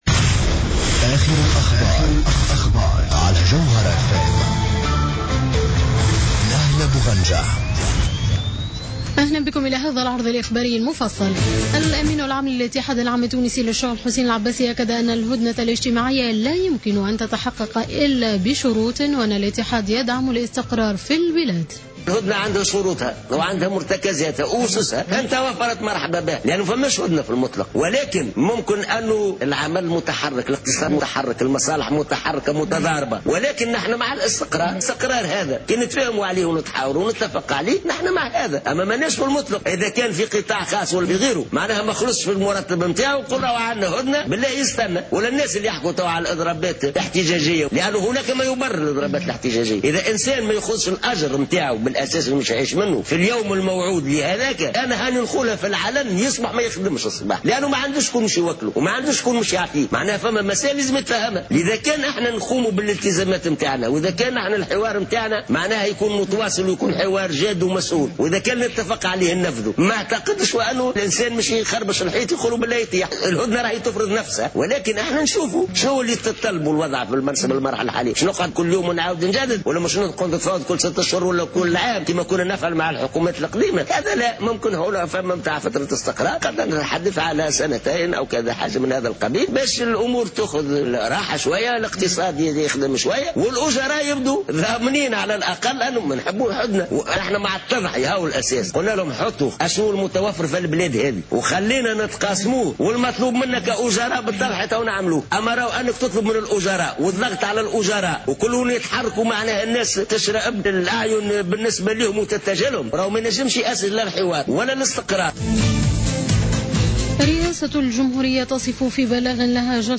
نشرة أخبار منتصف الليل ليوم السبت 17-01-15